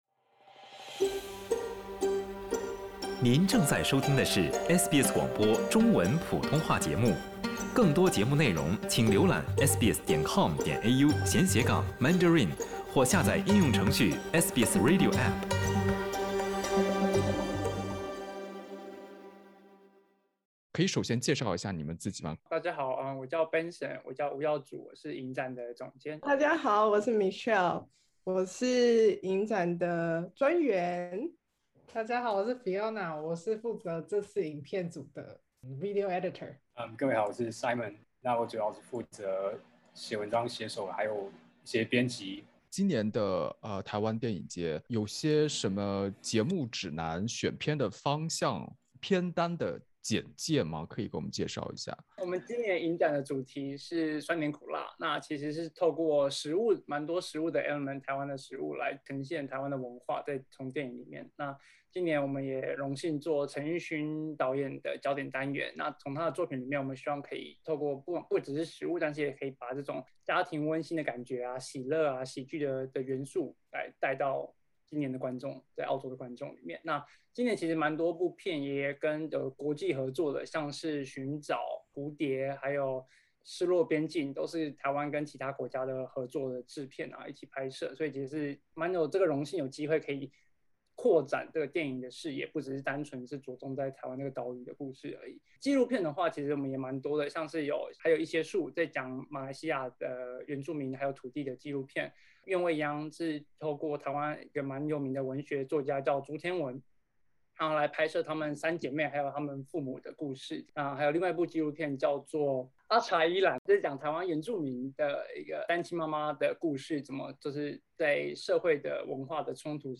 受到疫情影响，在悉尼举办的台湾影展，连续两年改采网上举办。今年年度焦点影人为擅长喜剧风格的陈玉勋导演。点击首图收听采访音频。